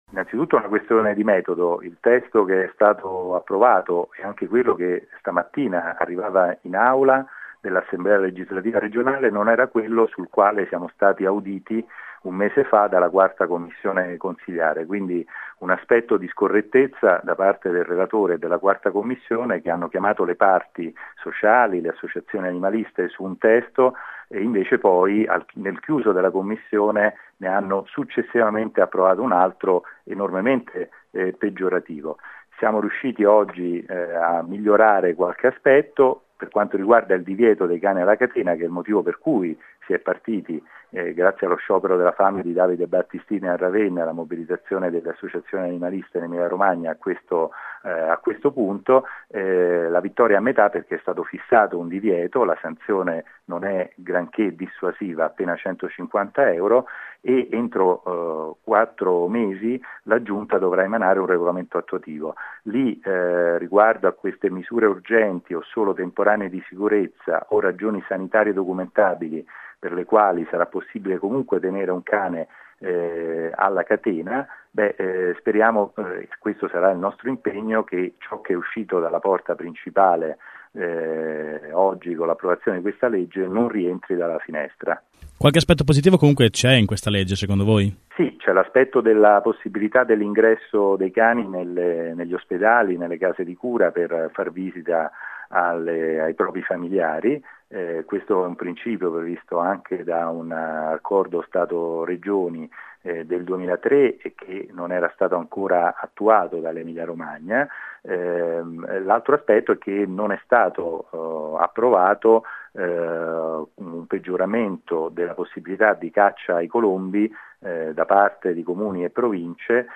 ai nostri microfoni